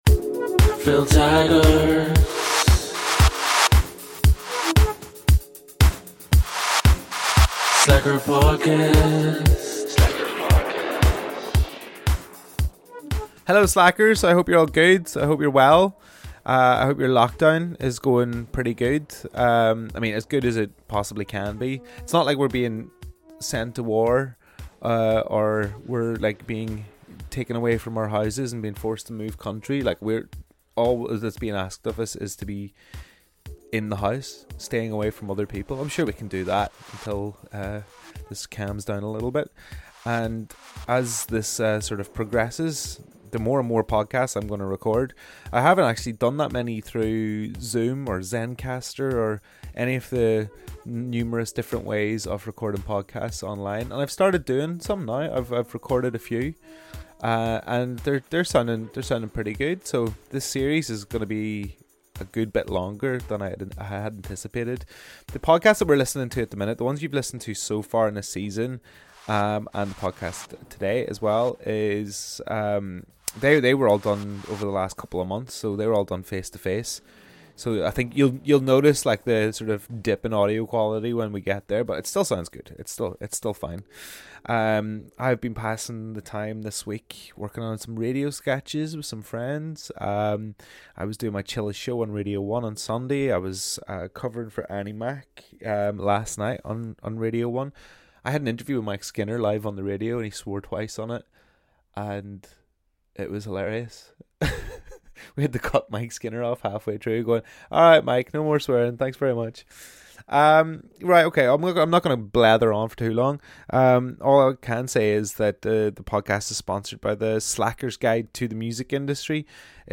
Gus from the band invited me into his home in East London.